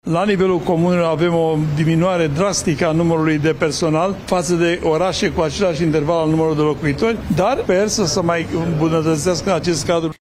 Președintele Asociației Comunelor din România, Emil Drăghici: În comune avem o diminuare drastică a numărului de personal, față de orașele cu același număr de locuitori